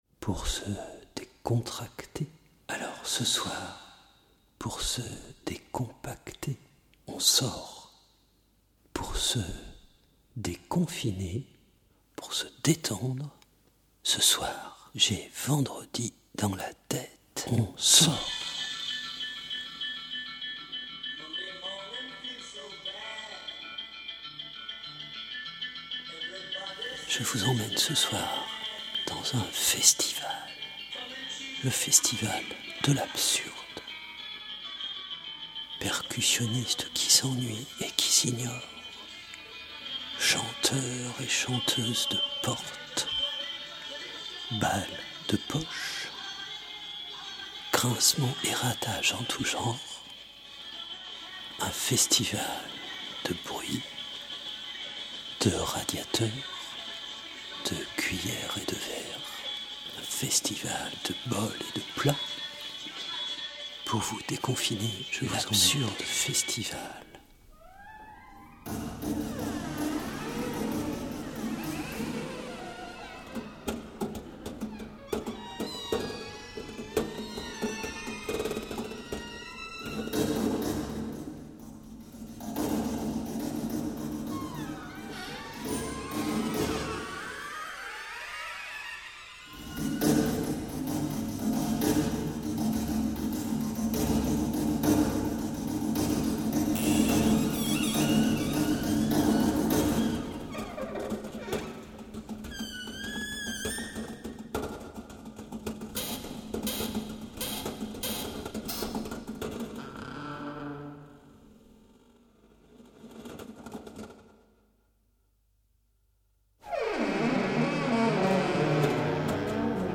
Créations radiophoniques, électroacoustiques, poésie sonore, extraits de spectacle, extraits d’ateliers...
♦ Billet d'humeur